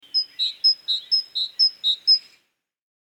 Die Kohlmeise
Kohlmeise_audio.mp3